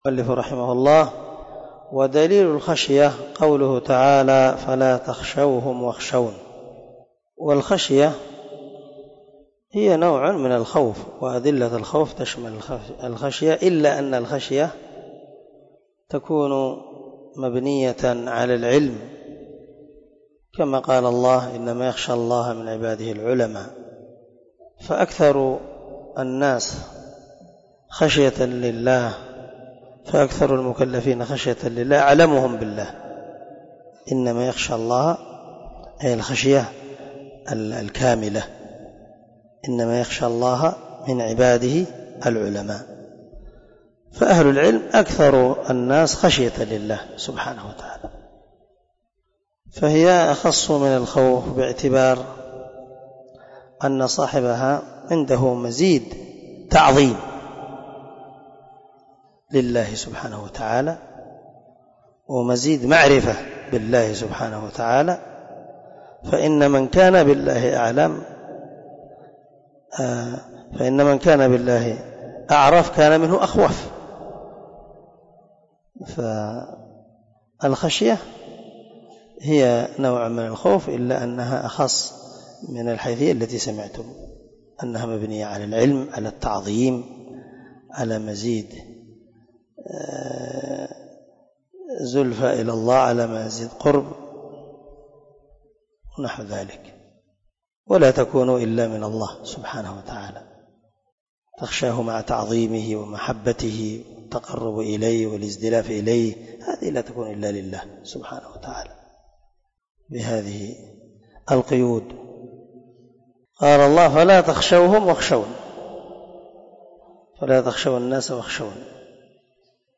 🔊 الدرس 17 من شرح الأصول الثلاثة